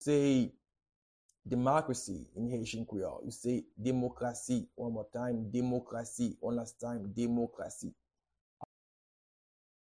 a native Haitian voice-over artist can be heard in the recording here :
How-to-say-Democracy-in-Haitian-Creole-Demokrasi-pronunciation-by-a-native-Haitian-teacher.mp3